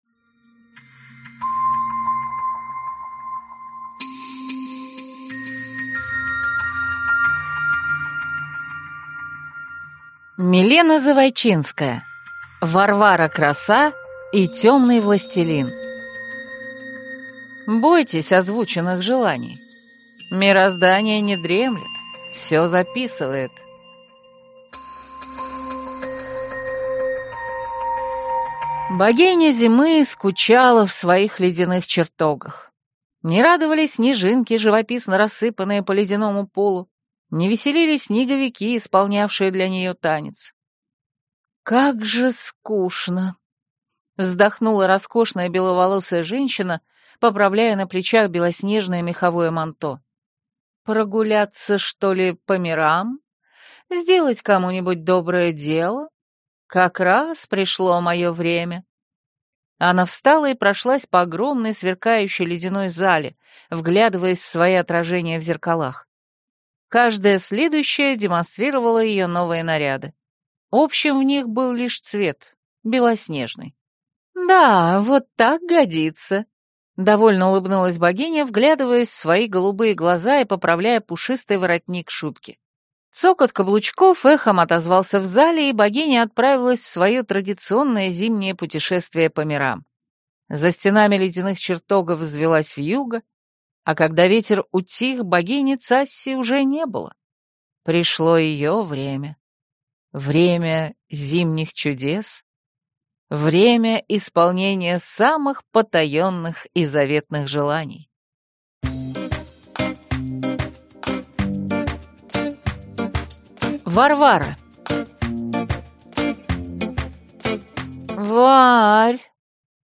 Аудиокнига Варвара-краса и Тёмный властелин - купить, скачать и слушать онлайн | КнигоПоиск